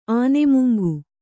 ānemuŋgu